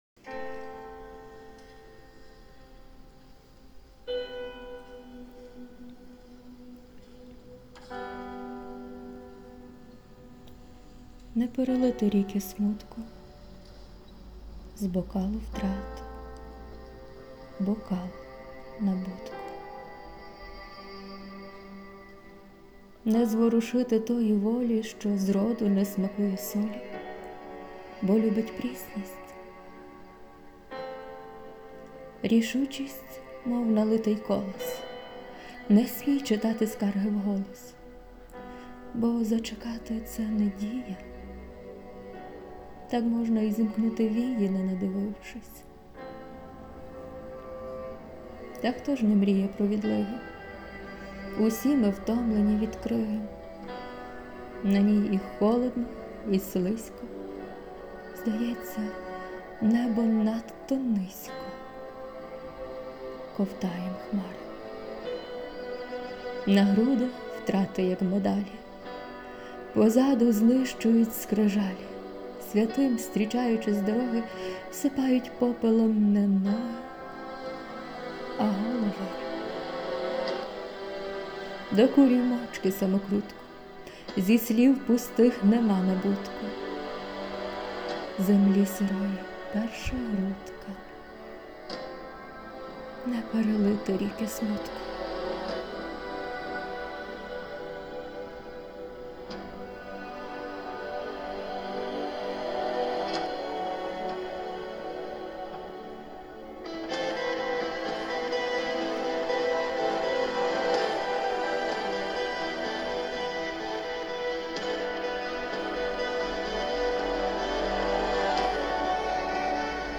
Фон – Hans Zimmer. Time
ВИД ТВОРУ: Вірш